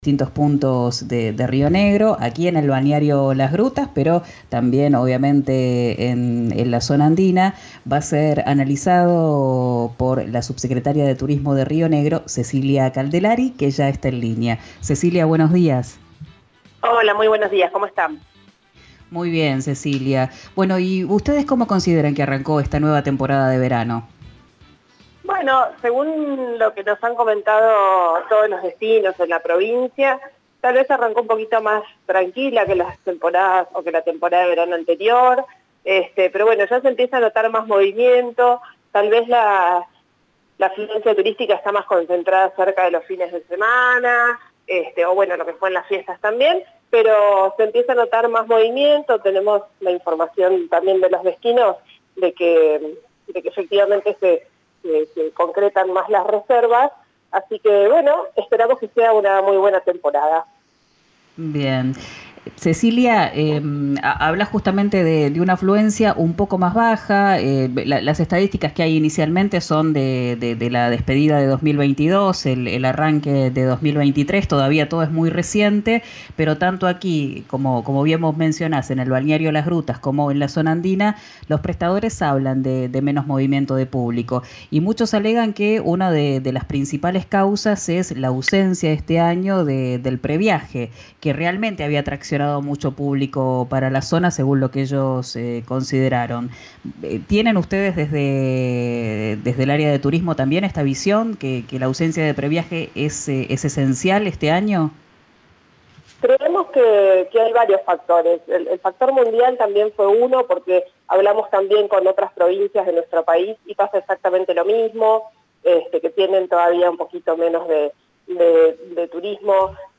Así lo informó la subsecretaria de Turismo de Río Negro, Cecilia Caldelari, en diálogo con "Quién dijo verano" por RÍO NEGRO RADIO.
Escuchá a la subsecretaria de Turismo de Río Negro, Cecilia Caldelari, en “Quién dijo verano”, por RÍO NEGRO RADIO